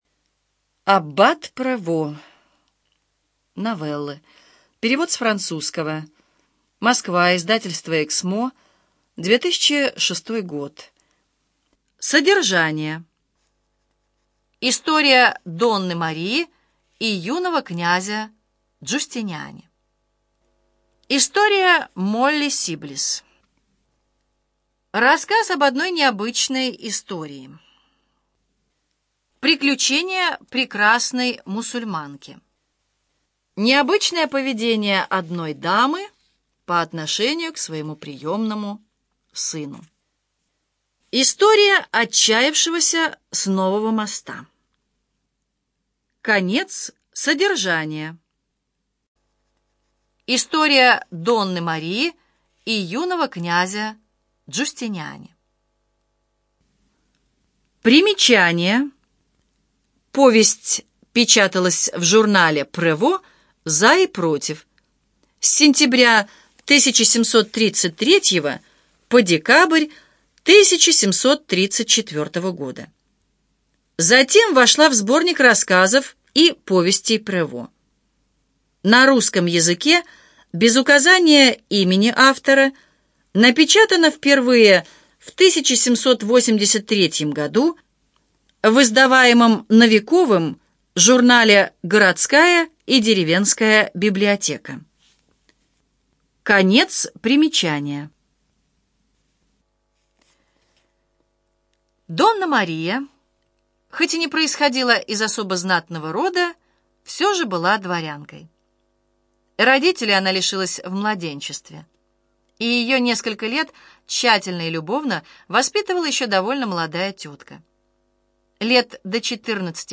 ЖанрКлассическая проза
Студия звукозаписиЛогосвос